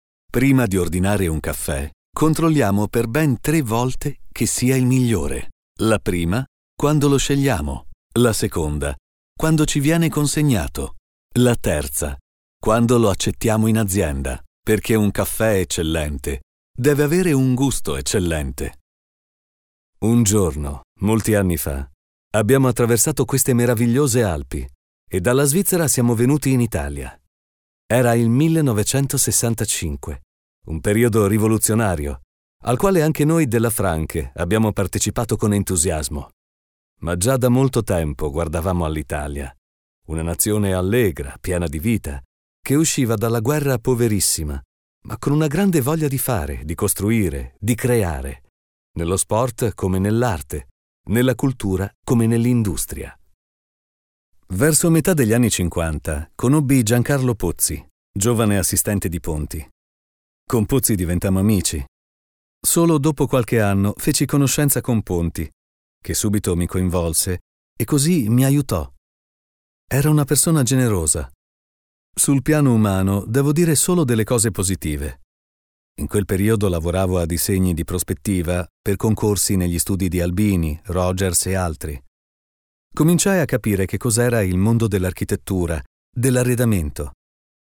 Sprecher italienisch.
Sprechprobe: eLearning (Muttersprache):
Italian voice over talent.